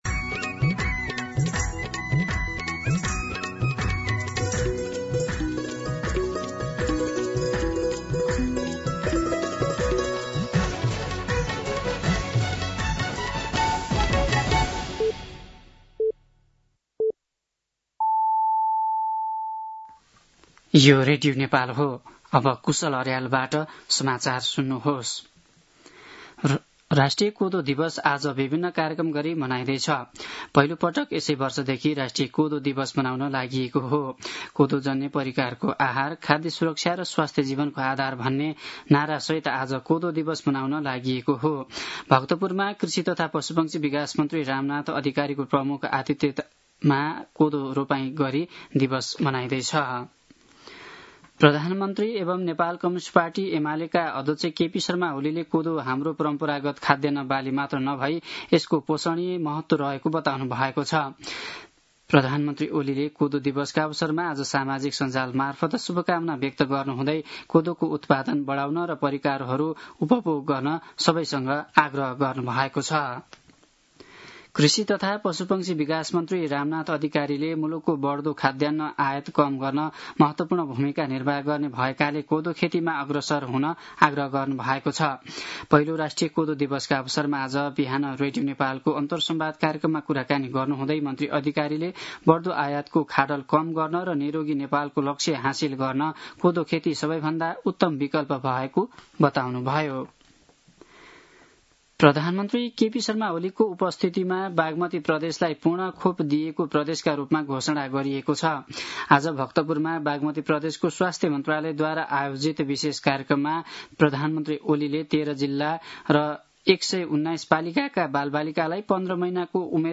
दिउँसो ४ बजेको नेपाली समाचार : १६ साउन , २०८२
4-pm-Nepali-News.mp3